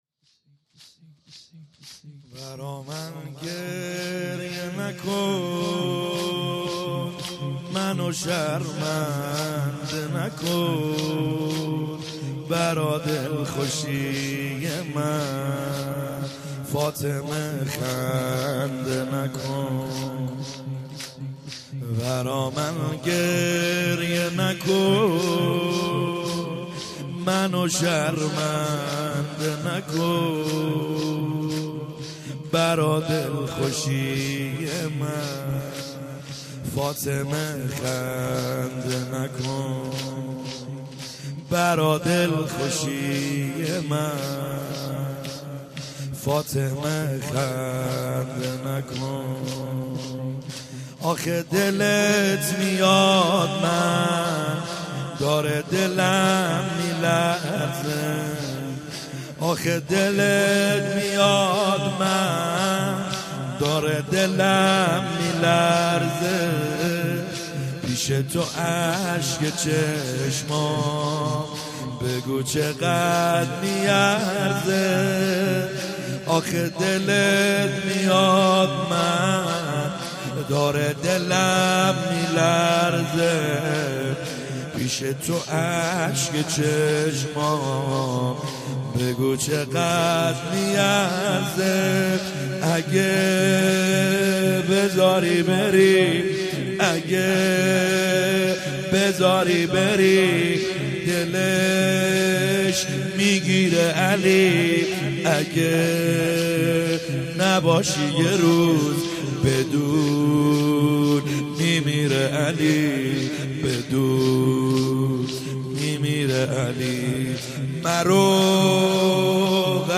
خیمه گاه - بیرق معظم محبین حضرت صاحب الزمان(عج) - زمینه | برای من گریه نکن